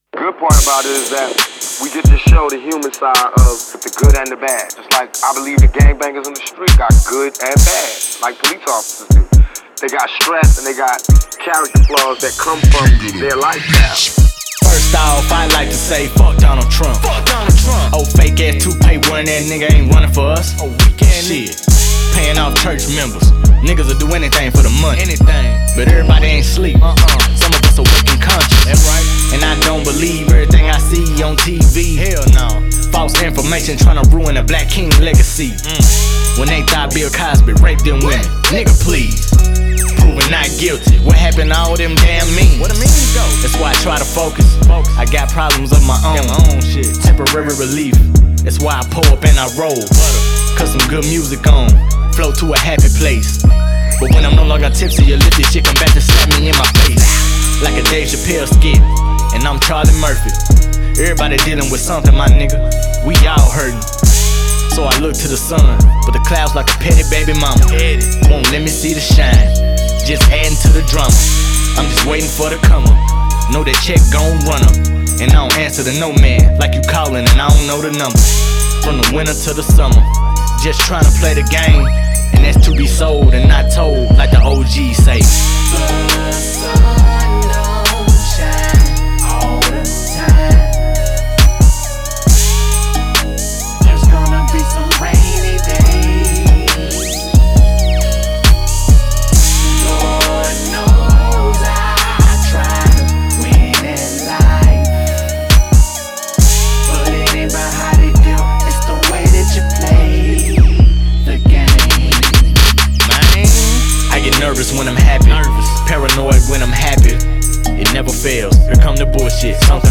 Hiphop
Dirty South conscious rap